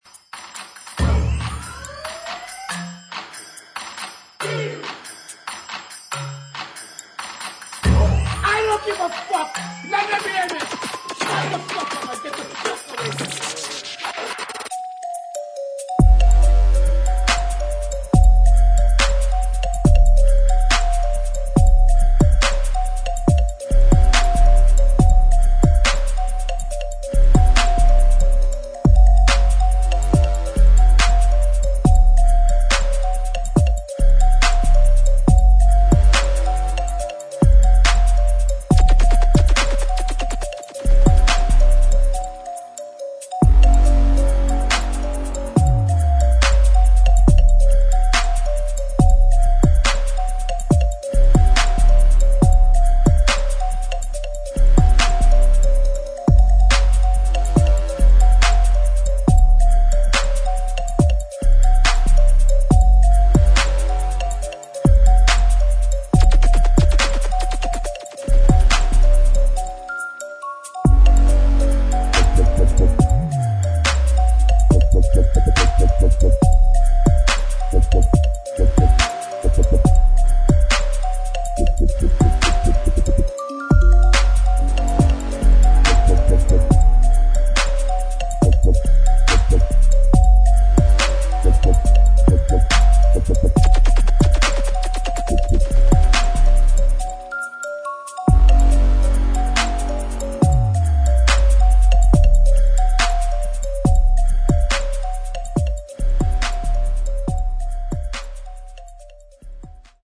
[ DUBSTEP / GRIME ]